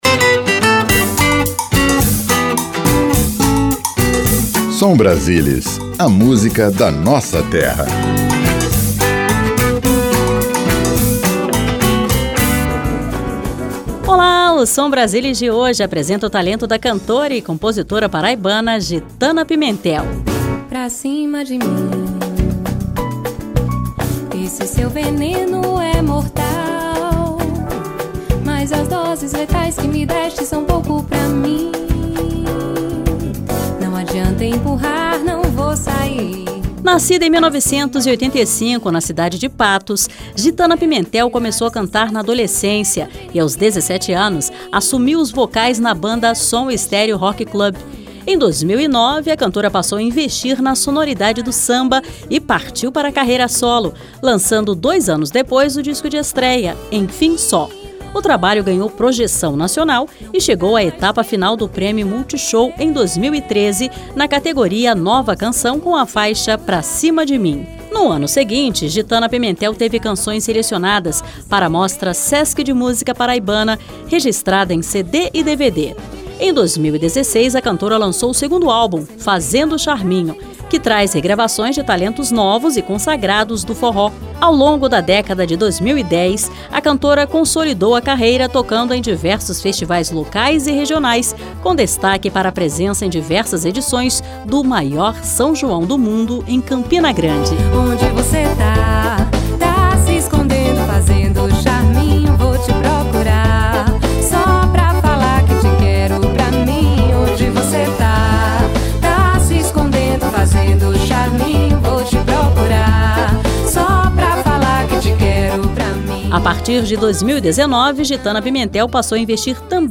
Seleção Musical:
Samba